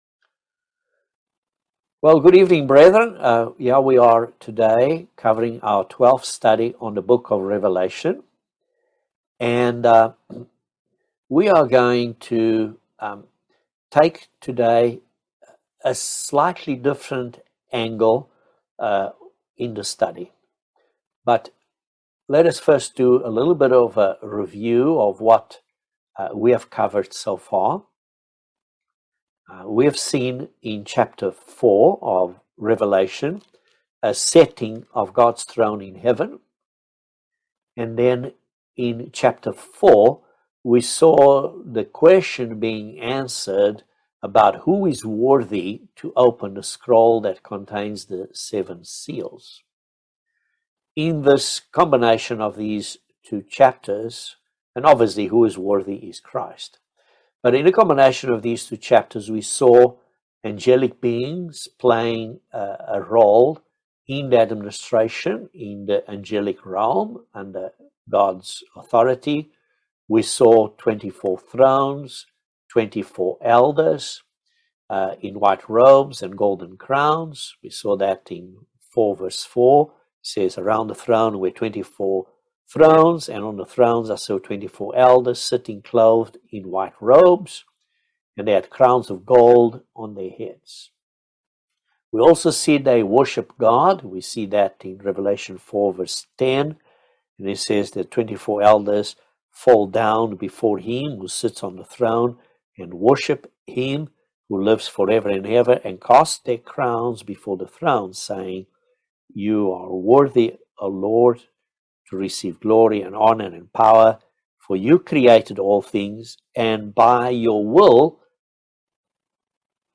Bible Study No 12 of Revelation